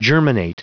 Prononciation du mot germinate en anglais (fichier audio)
Prononciation du mot : germinate